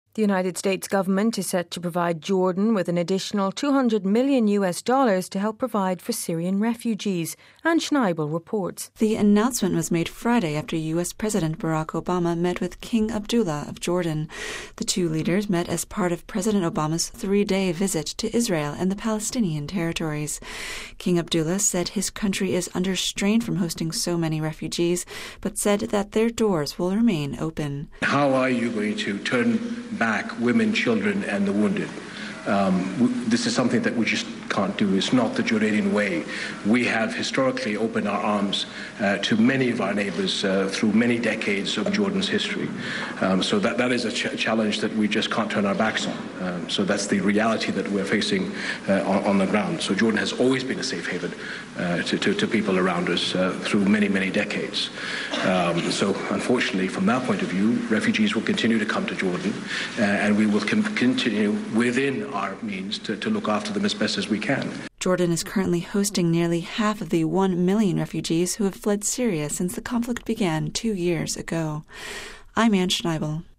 full report